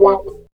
28 GUIT 2 -L.wav